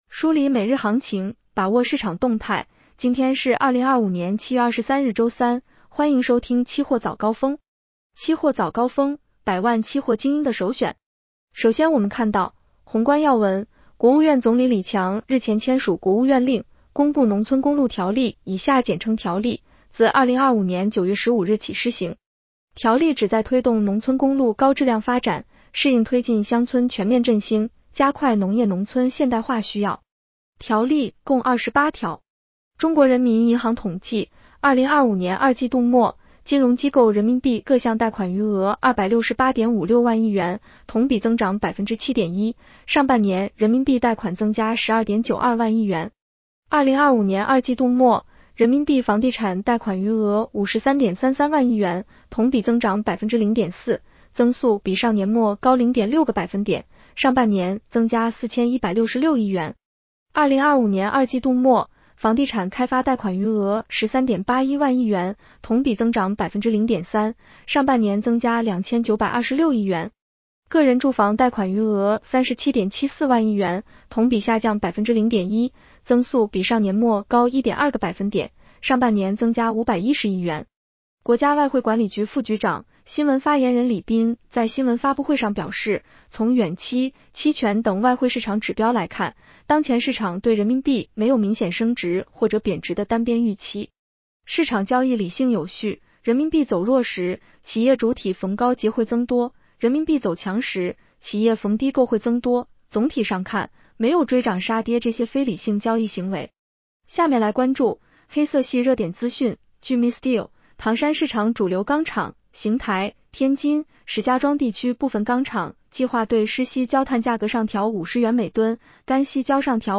期货早高峰-音频版
期货早高峰-音频版 女声普通话版 下载mp3 宏观要闻 1.